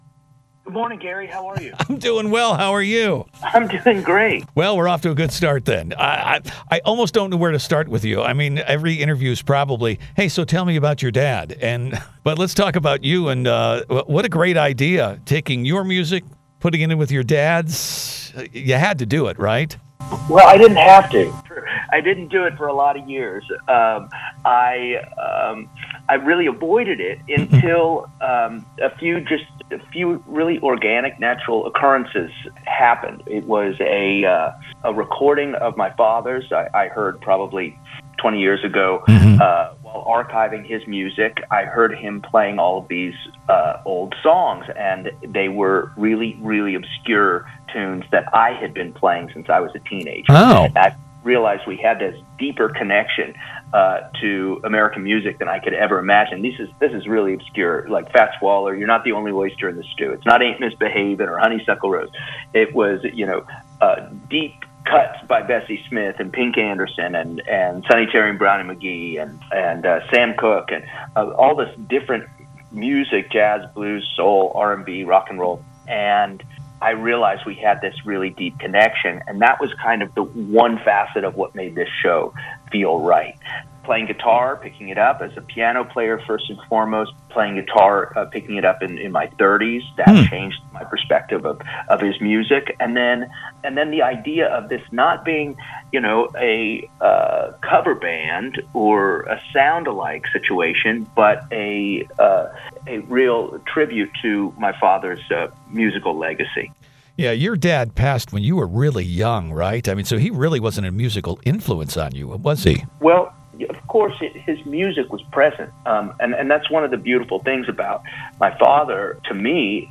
AJ Croce Interview https